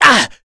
Ezekiel-Vox_Damage_01_kr.wav